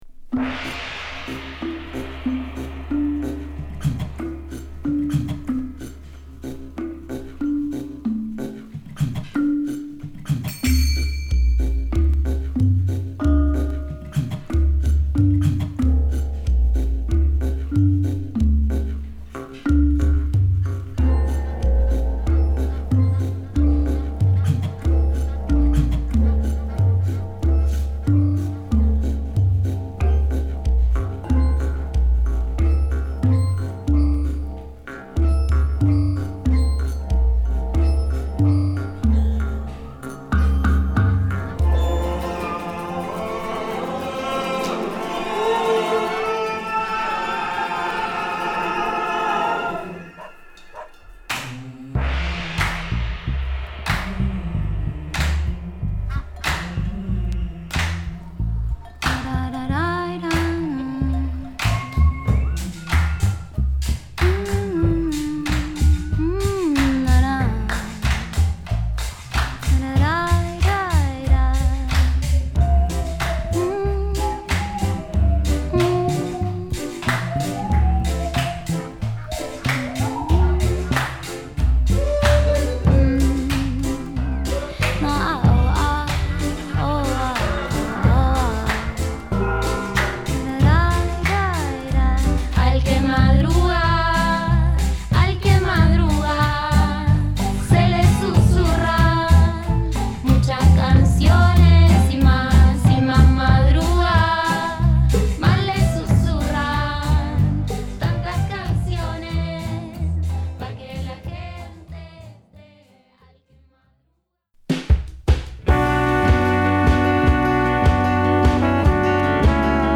＊試聴はA1→A3→B2→C2です。